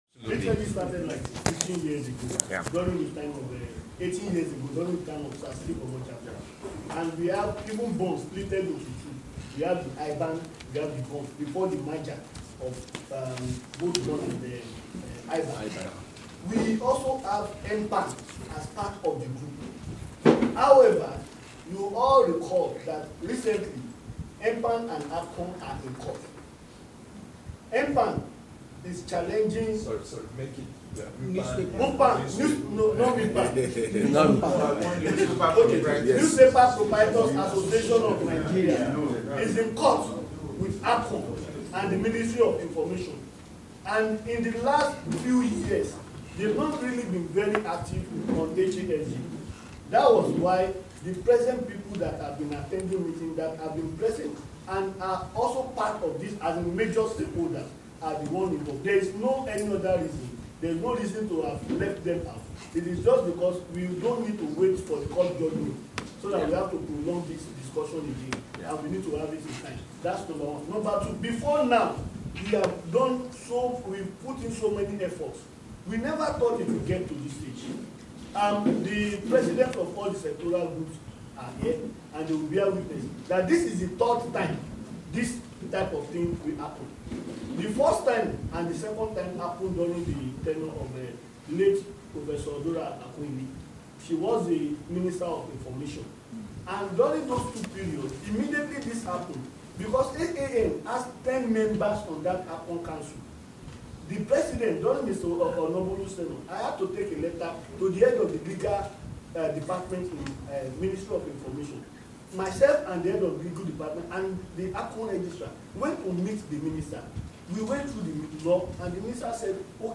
Press briefing of HASG